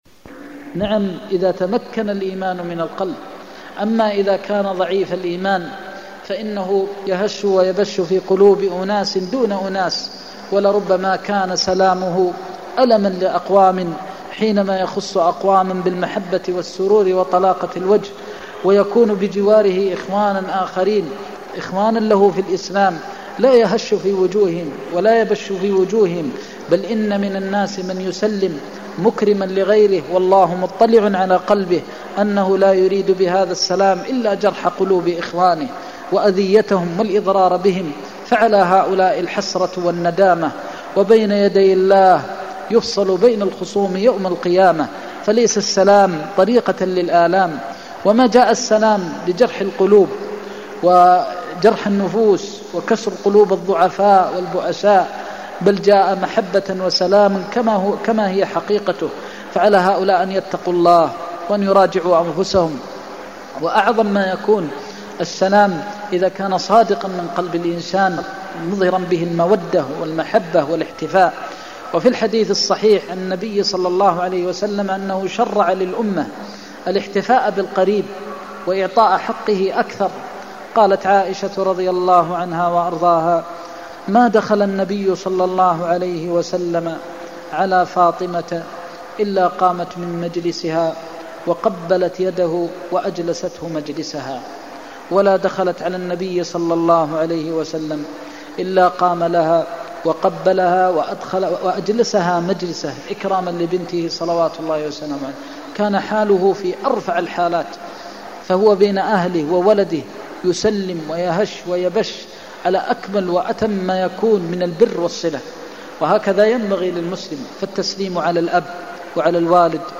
المكان: المسجد النبوي الشيخ: فضيلة الشيخ د. محمد بن محمد المختار فضيلة الشيخ د. محمد بن محمد المختار اللباس (2)